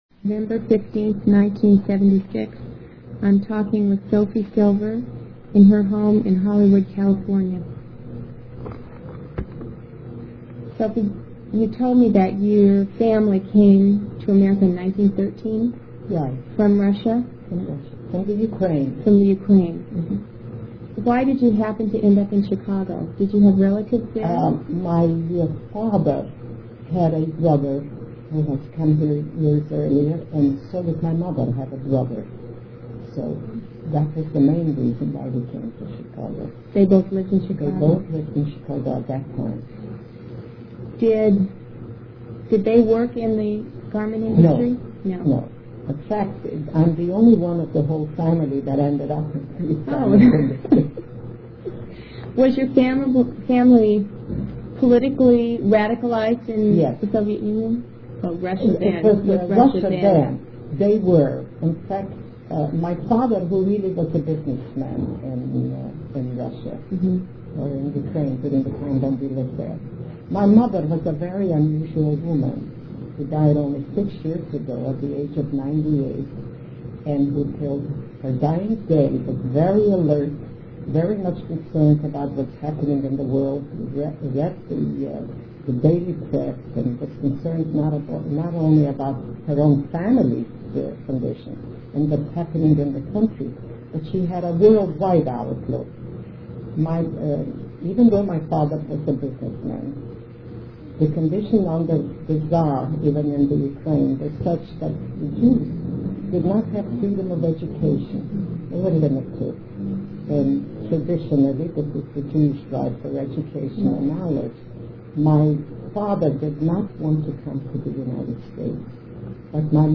INTERVIEW DESCRIPTION
The quality of this interview is poor
There are several interruptions during the interview. TOPICS - family background; working conditions; attitudes towards the ILGWU; background of the workers in the union; and the Chicago general strike of 1932;1932 general strike in Chicago; internal conflict in the ILGWU; contradiction between her socialist beliefs and union policies; becoming organizer and Business Agent for ILGWU in Los Angeles; working conditions during the Depression; and her attitudes towards WW II;